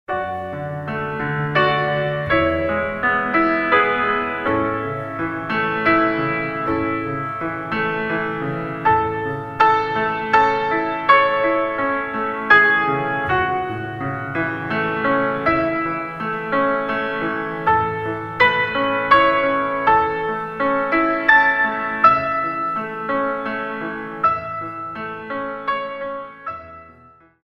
In 3